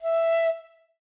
flute_e.ogg